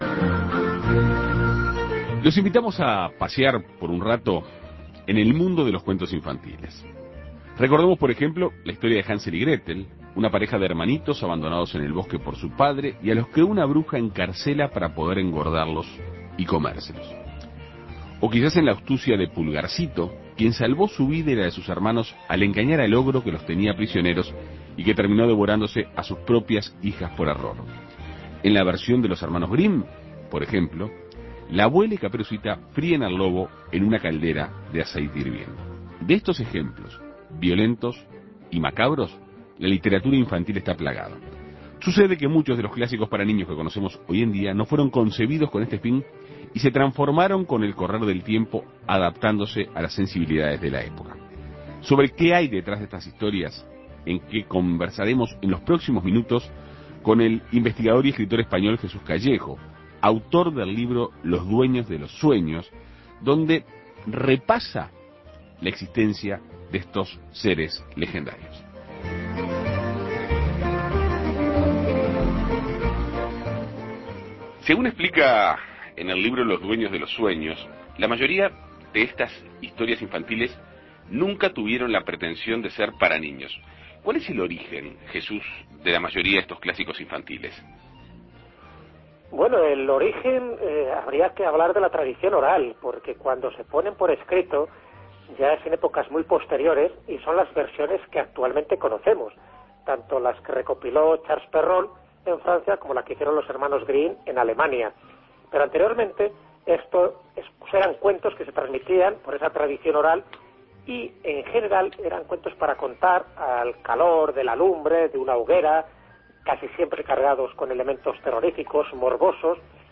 Según ella, no hay nada más sexy, feroz y desopilante que la realidad, y la mejor manera de contarla es a través del periodismo. En Perspectiva Segunda mañana dialogó con la periodista argentina.